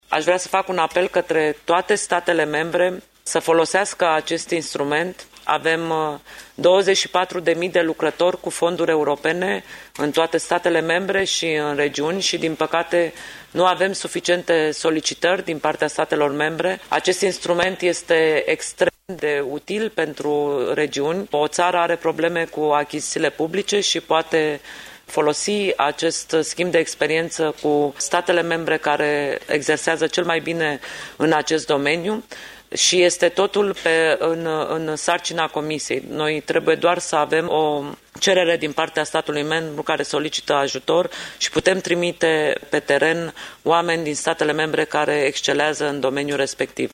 La una dintre întâlnirile cu presa, comisarul Corina Crețu a vorbit despre incapacitatea administrațiilor publice în absorbirea fondurilor europene, aceasta fiind, potrivit declarațiilor, una dintre prioritățile mandatului său.